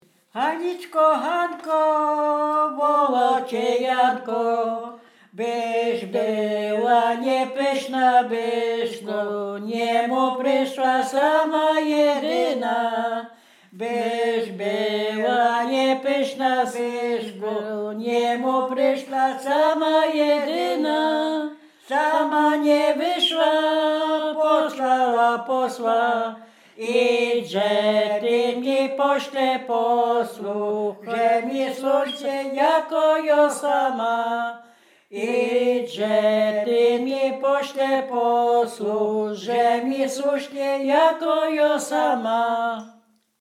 Weselna
liryczne miłosne weselne wesele